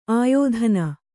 ♪ āyōdhana